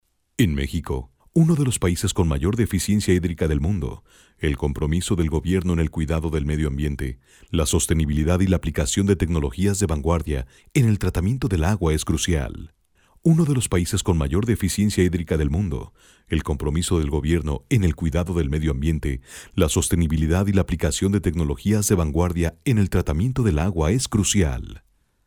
International voice overs